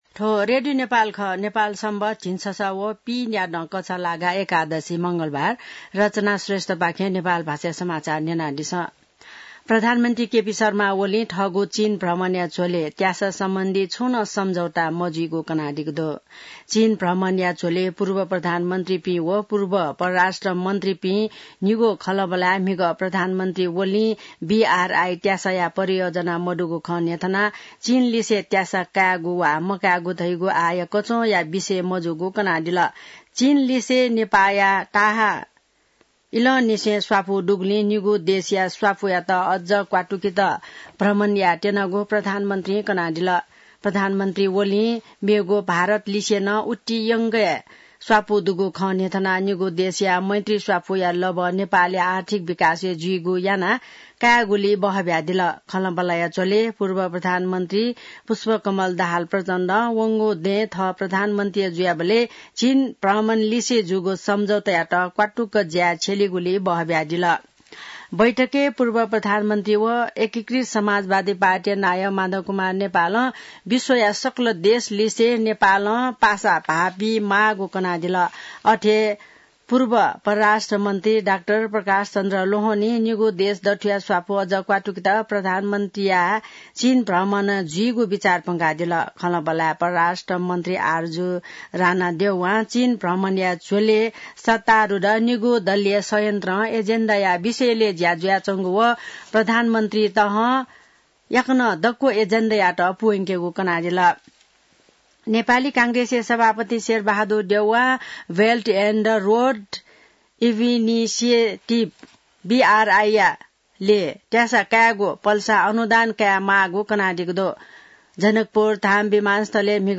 नेपाल भाषामा समाचार : १२ मंसिर , २०८१